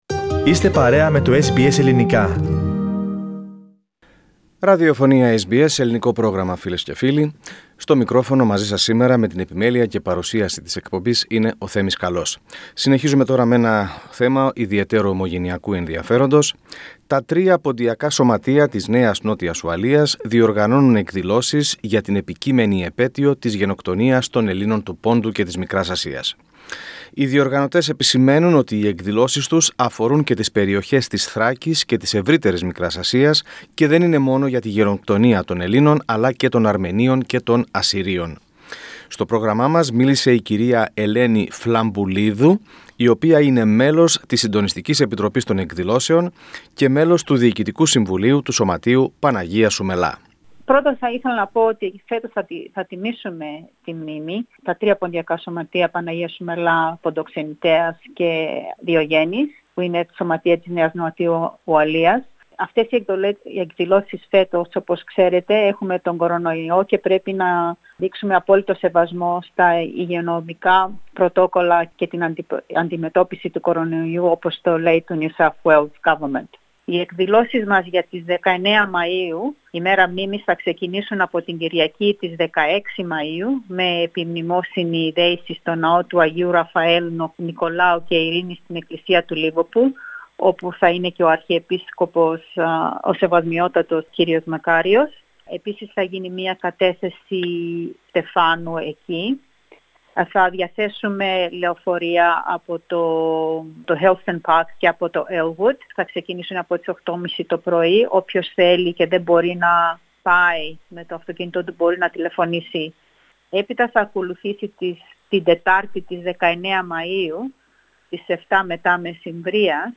Μίλησε στο πρόγραμμά μας, εκ μέρους των διοργανωτών, και μας επισήμανε ότι οι εκδηλώσεις αφορούν και τις περιοχές της Θράκης και της ευρύτερης Μικράς Ασίας και δεν είναι μόνο για την Γενοκτονία των Ελλήνων αλλά και των Αρμενίων και των Ασσυρίων, από τις τότε οθωμανικές δυνάμεις.